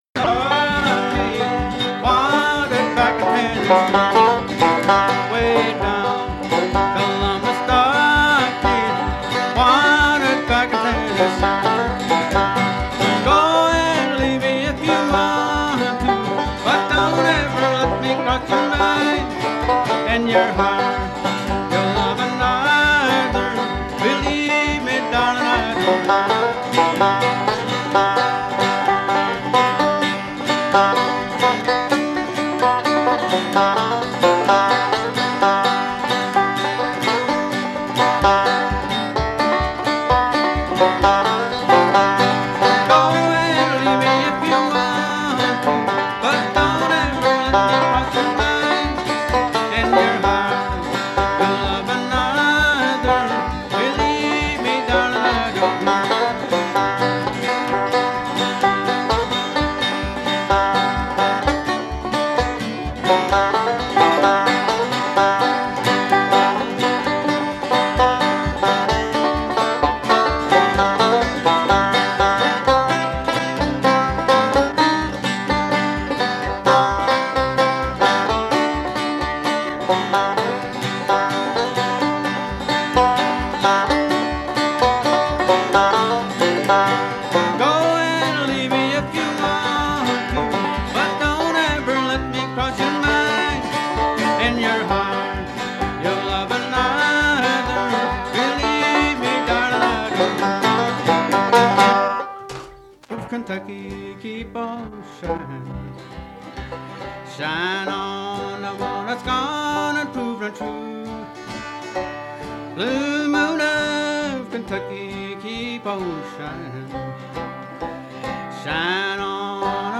Country music